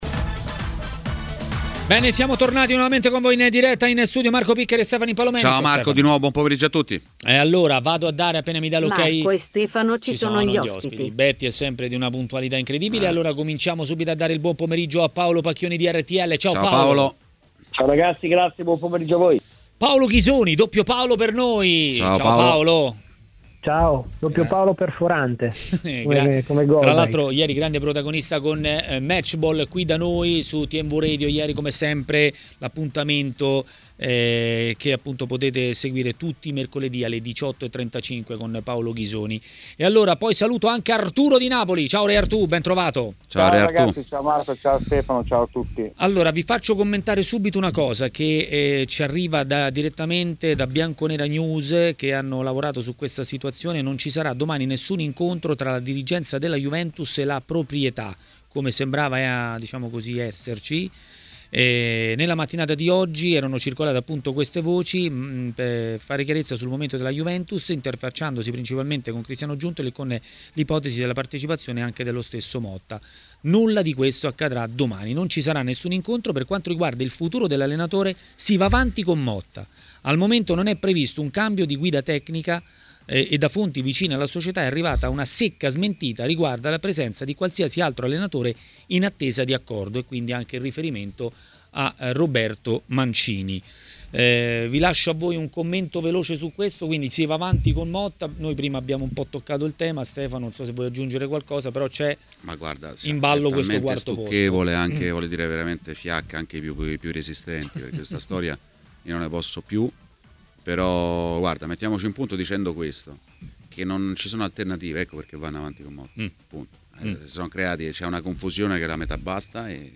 L'ex attaccante e tecnico Arturo Di Napoli è stato ospite di Maracanà, trasmissione di TMW Radio.